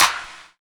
09.3 CLAP.wav